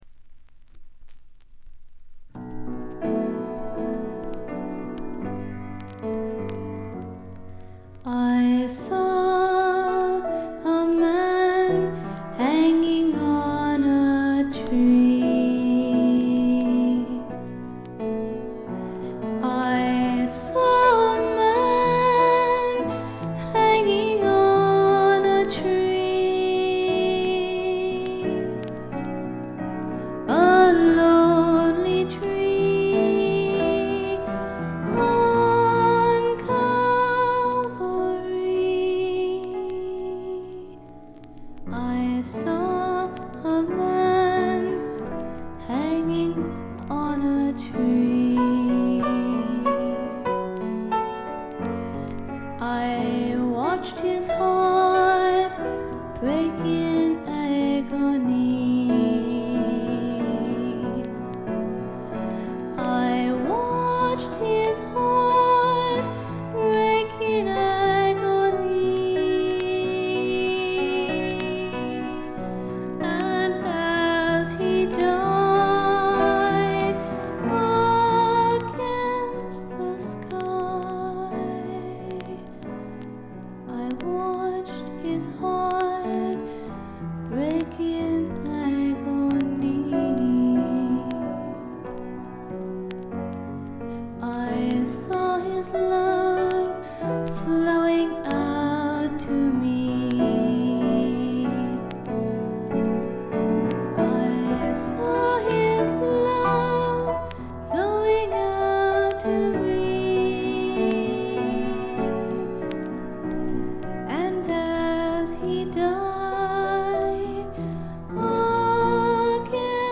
B2中盤でチリプチ周回気味。
演奏はほとんどがギターの弾き語りです。
試聴曲は現品からの取り込み音源です。